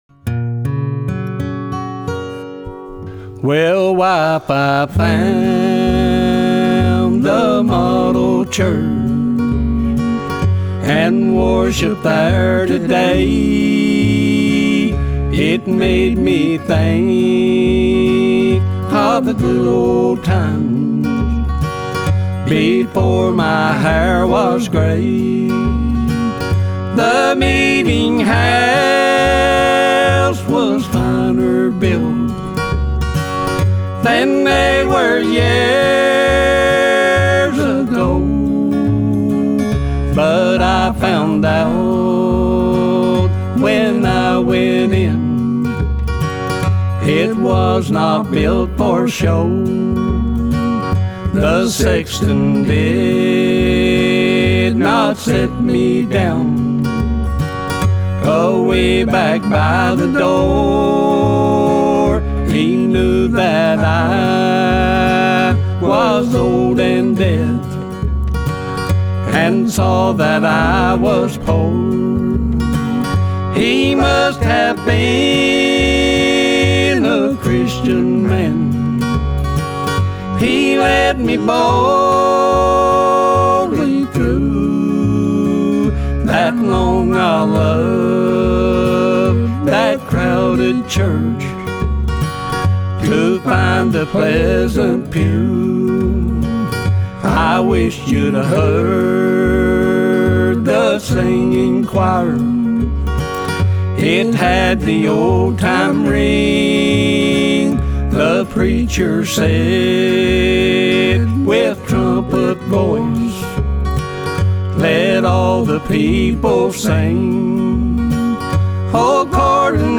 Banjo & Vocal
Mandolin & Vocal
Bass & Vocal
Guitar & Vocal